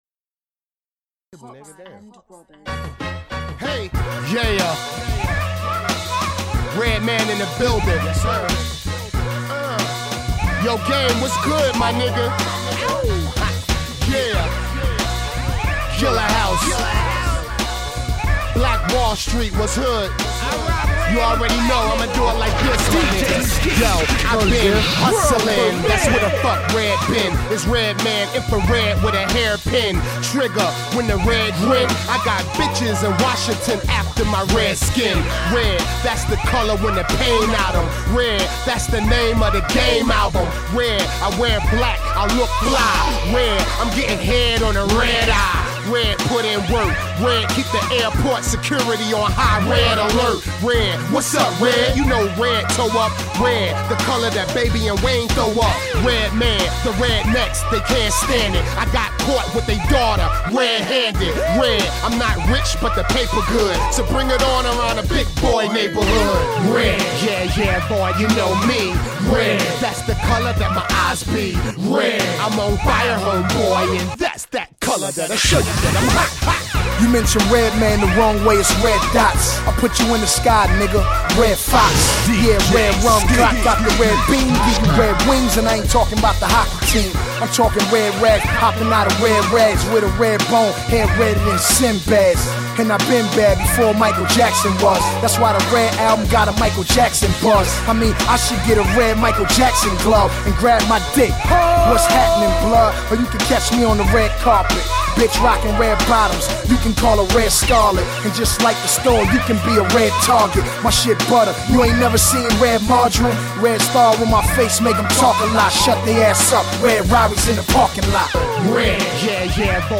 The track is short; we get one verse each.